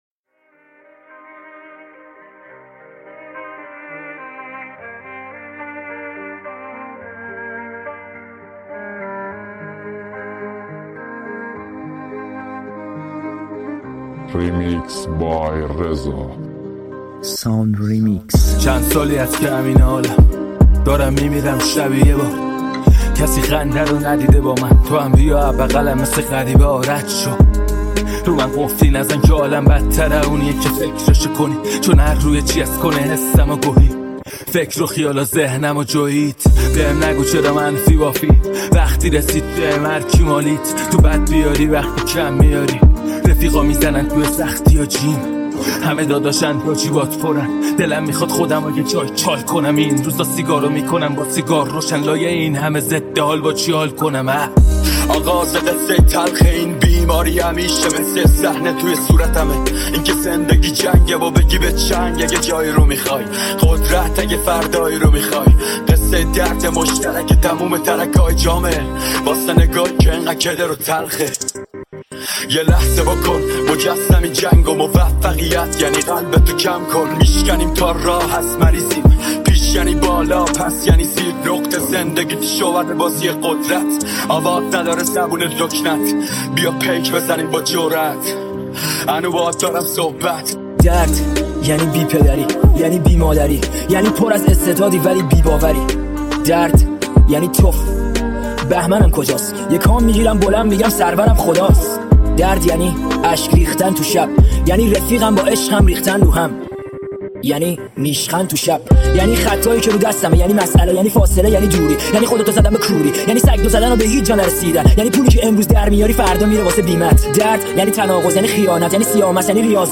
ریمیکس شاد
ریمیکس رپ